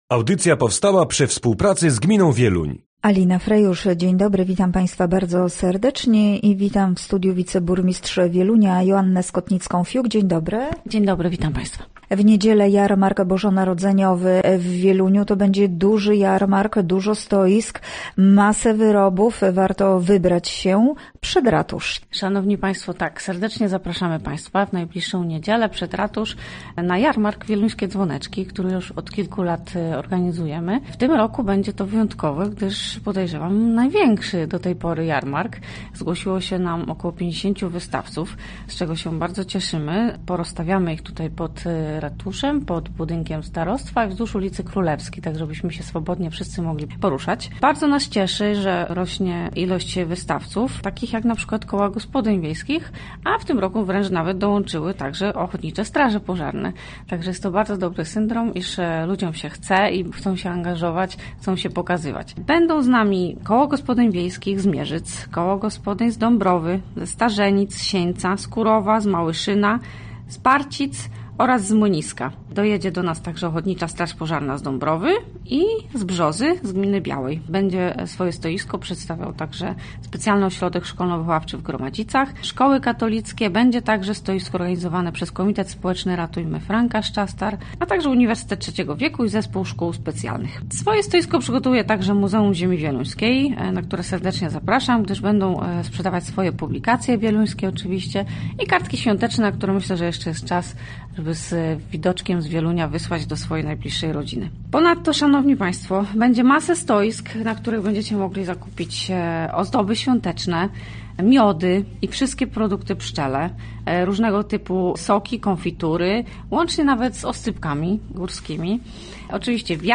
Gościem Radia ZW była Joanna Skotnicka-Fiuk, wiceburmistrz Wielunia